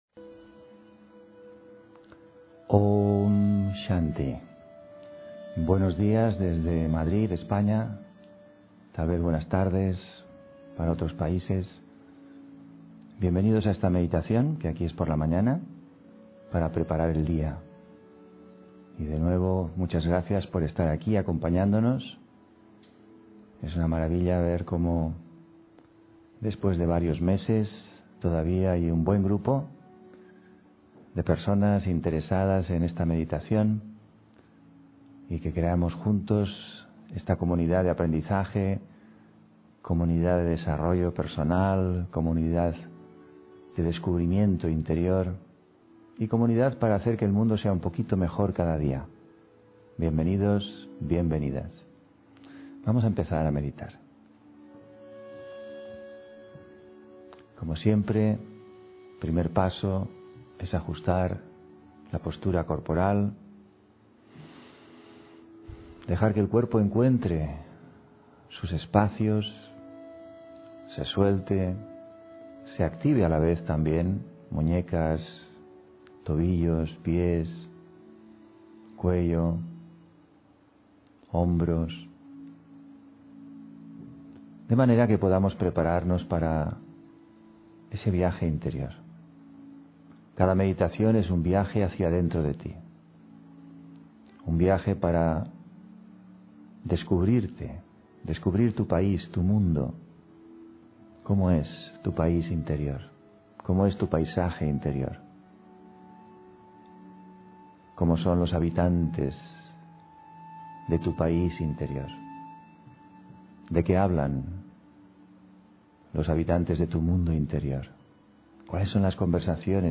Meditación Raja Yoga con charla: El entusiasmo, tu oxígeno (18 Noviembre 2020) On-line d ...